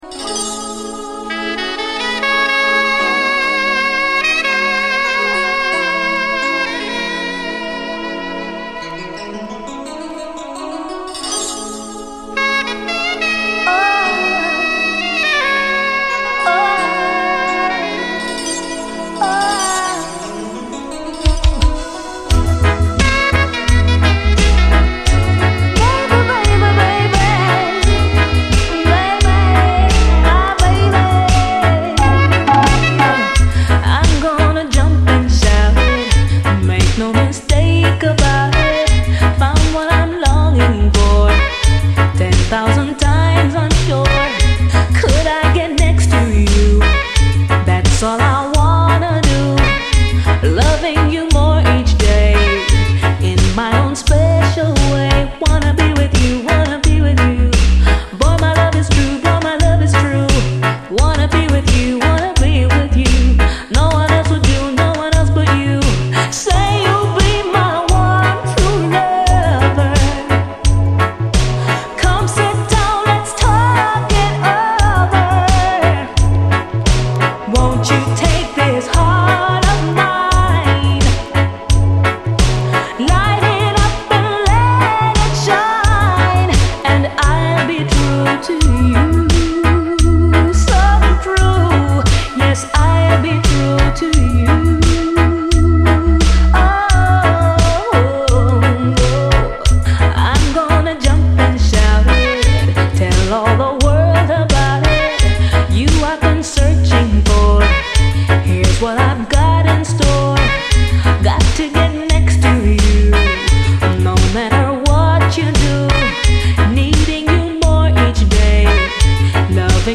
REGGAE
幻想的なイントロ＆シンセ、切なさこみ上げる90’Sラヴァーズ！